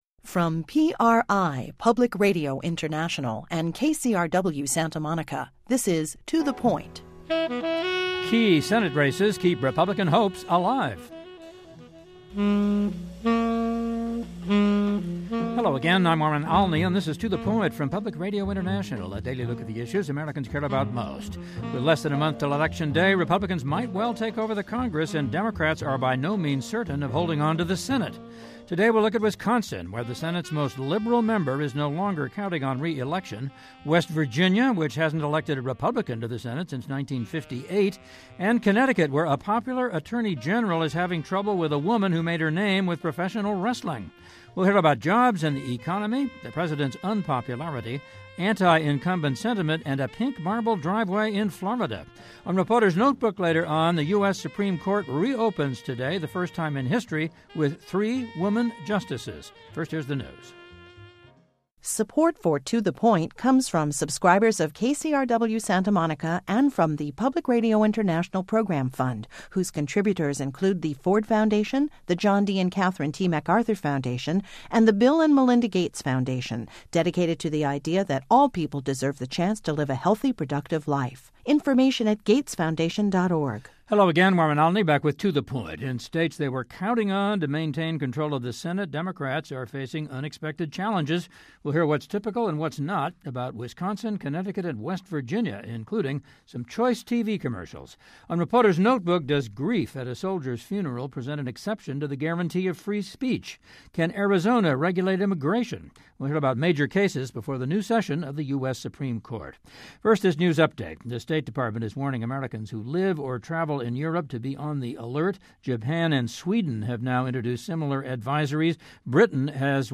In states they were counting on to maintain control of the Senate, Democrats are facing unexpected challenges. We hear what’s typical, and what’s not, about Wisconsin, Connecticut and West Virginia, including some choice TV commercials.